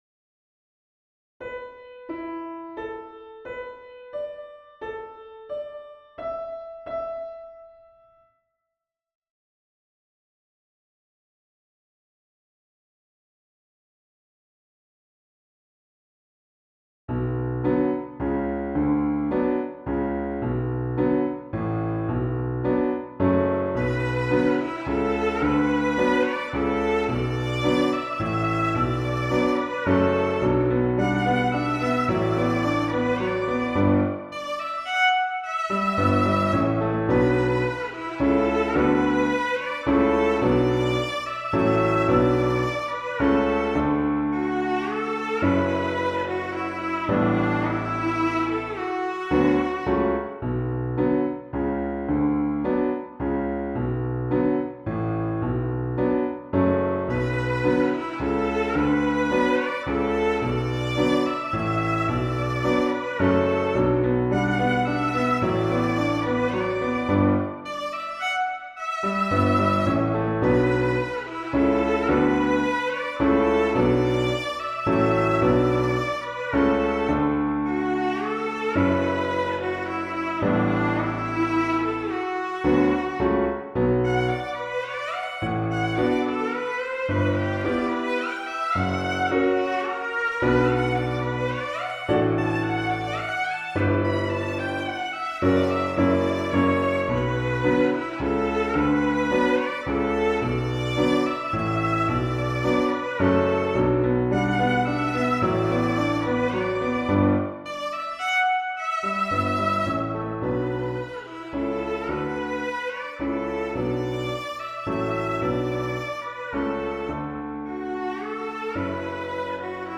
Viool en piano (2025)